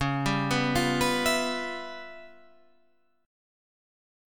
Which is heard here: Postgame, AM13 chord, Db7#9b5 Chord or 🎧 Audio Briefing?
Db7#9b5 Chord